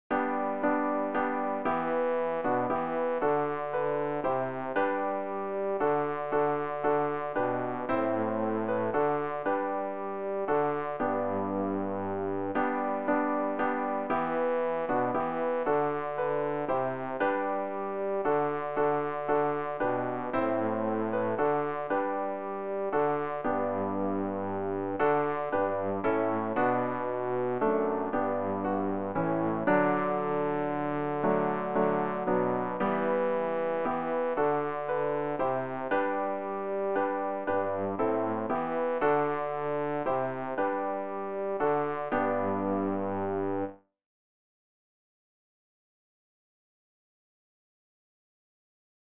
bass-rg-099-lobe-den-herrn-o-meine-seele.mp3